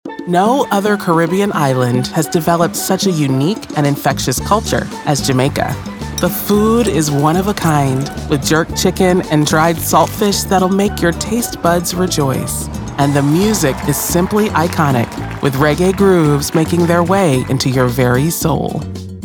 Never any Artificial Voices used, unlike other sites.
Explainer & Whiteboard Video Voice Overs
Adult (30-50) | Yng Adult (18-29)